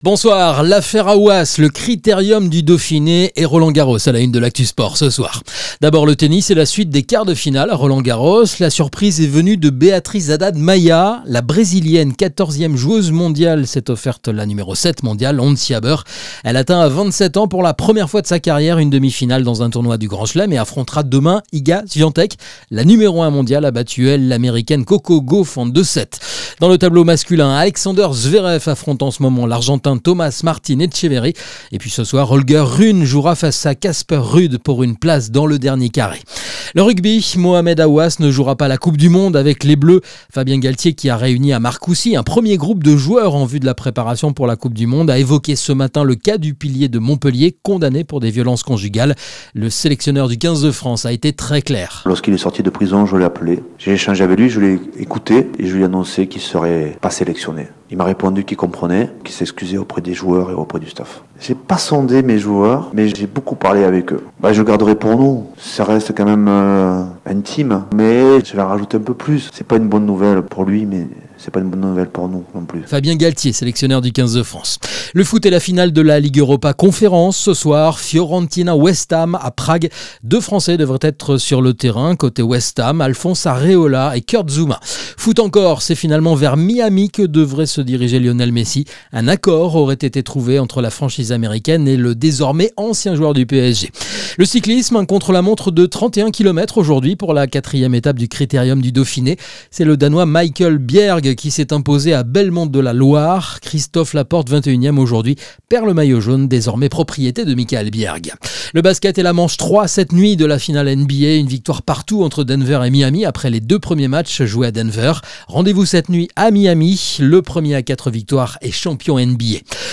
Le journal des sports